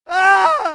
男人惨叫声音效免费音频素材下载